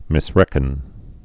(mĭs-rĕkən)